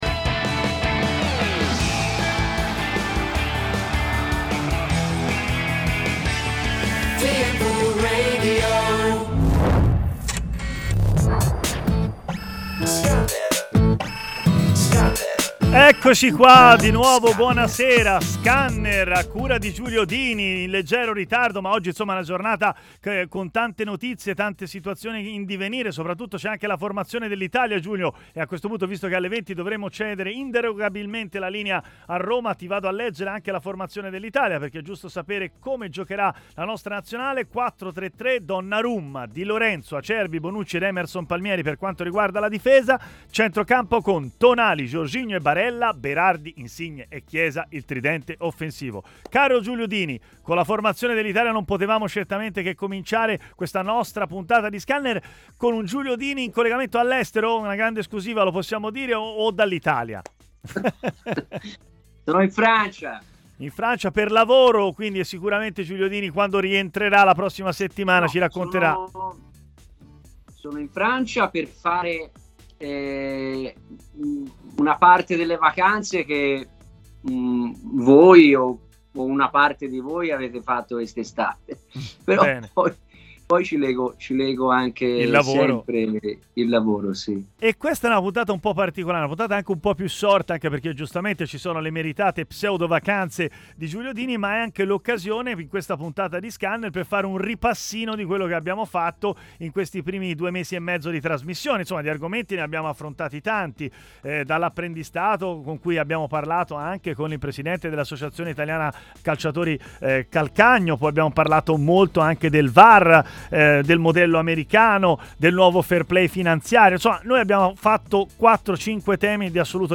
rubrica di approfondimento calcistico su TMW Radio